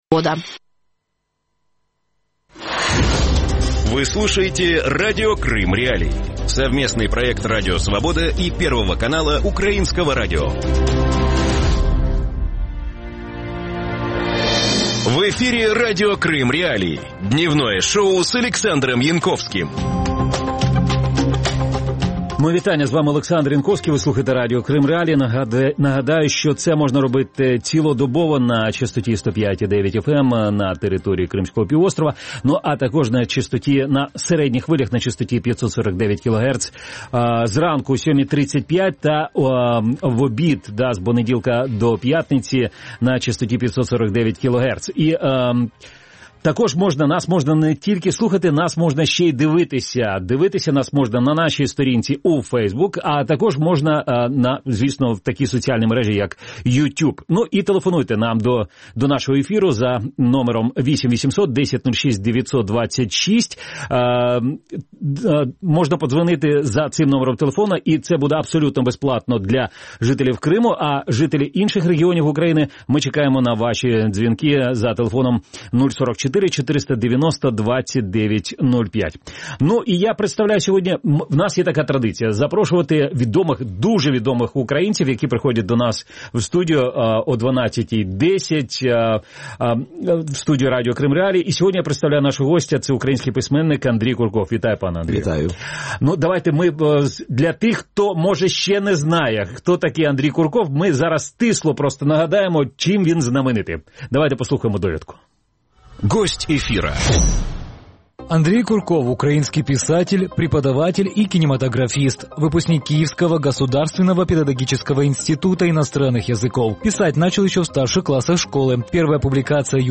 Гость студии: украинский писатель Андрей Курков.